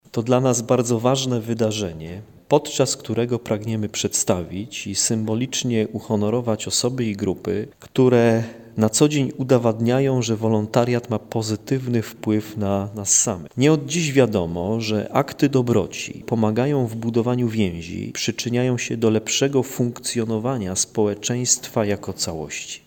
Wójt gminy Bojanów Sławomir Serafin przyznaje, że to ważne aby zauważać osoby i organizacje, które bezinteresownie niosą pomoc innym: